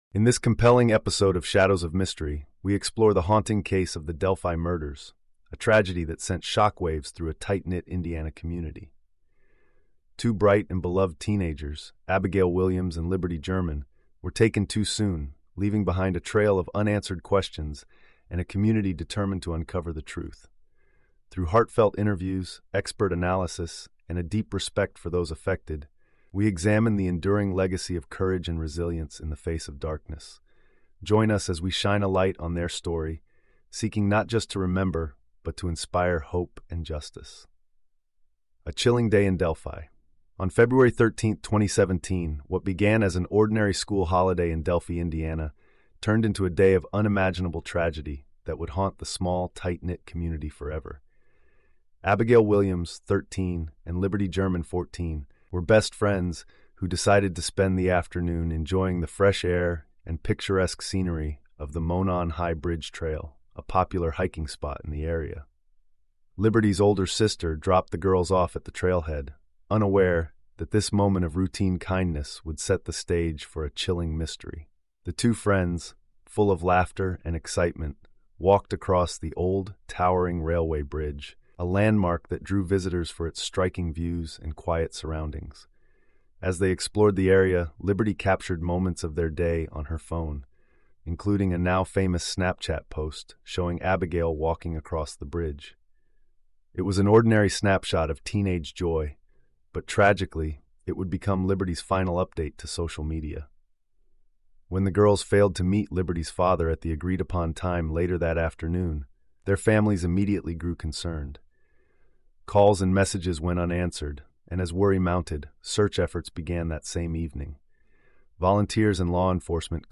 Through heartfelt interviews, expert analysis, and a deep respect for those affected, we examine the enduring legacy of courage and resilience in the face of darkness.